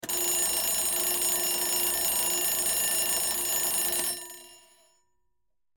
clock-alarm-8761.ogg